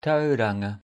Tauranga (Māori pronunciation: [ˈtaʉɾaŋa]) is a coastal city in the Bay of Plenty Region and the fifth most populous city of New Zealand, with an urban population of 158,300 (June 2022), or roughly 3% of the national population.